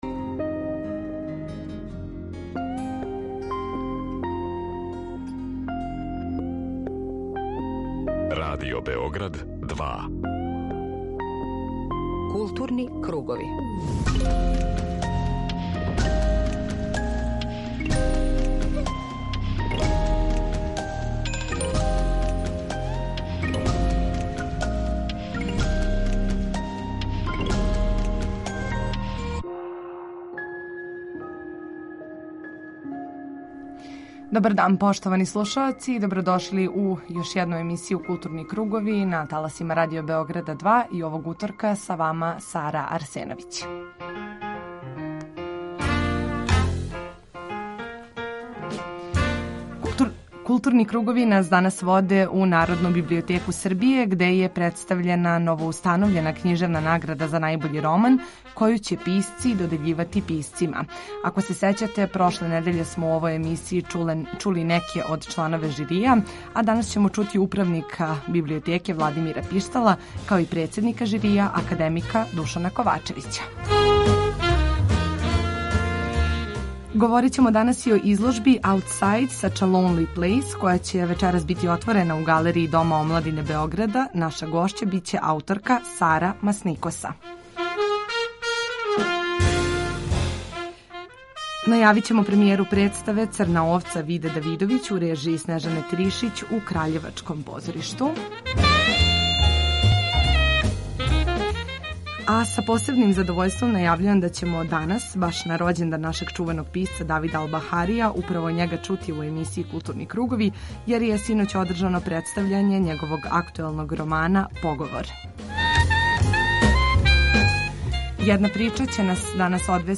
Културни кругови данас вас воде у Народну библиотеку Србије, где је представљена новоустановљена књижевна награда за најбољи роман, коју ће писци додељивати писцима. Чућемо управника Библиотеке Владимира Пиштала, као и чланове жирија, међу којима су наши познати и награђивани књижевници.